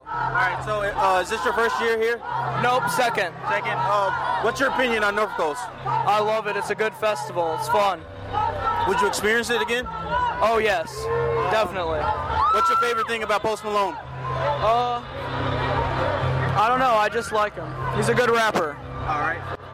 This brand new series will feature raw and straight to the point interviews with fans who toughed it out to get the coveted front row guardrail spot for their favorite artist’s sets.